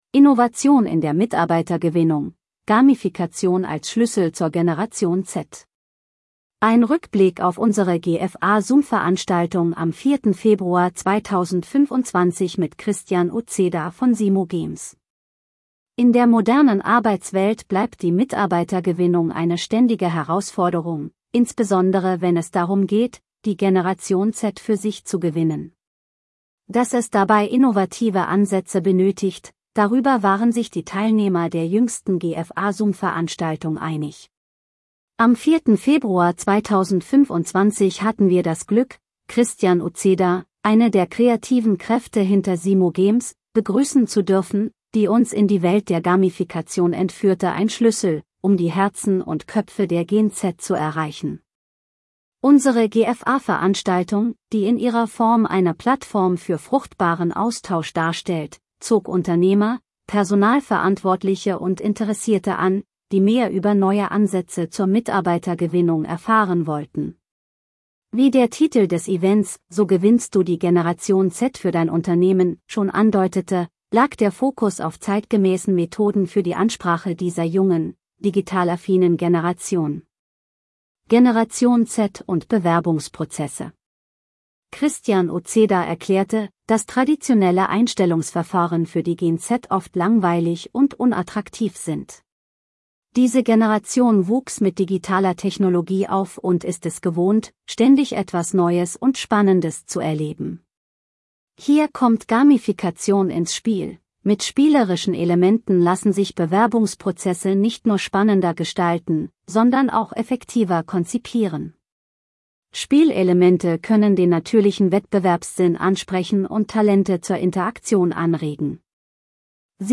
Vorgelesen von